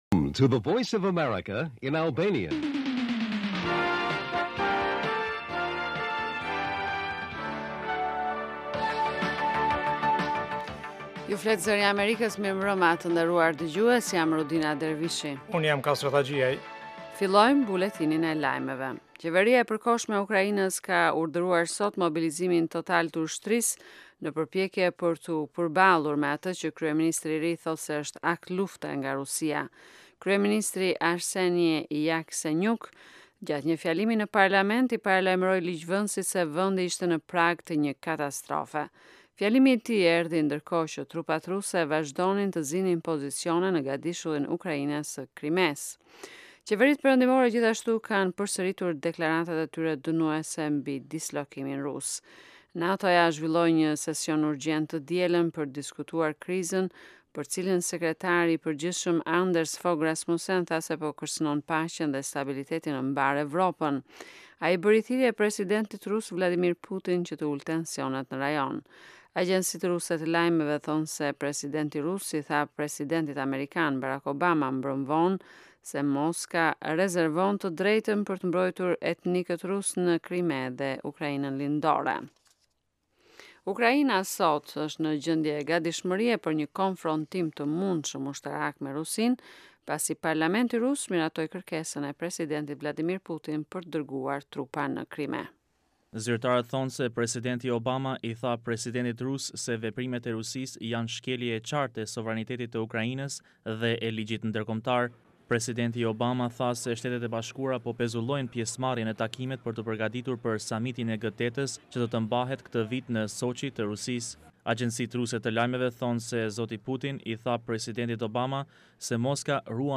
Lajmet e mbrëmjes